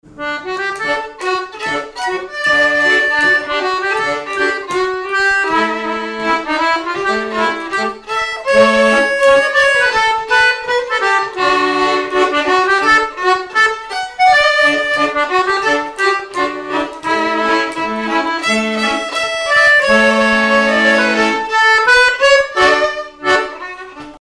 Miquelon-Langlade
violon
Pièce musicale inédite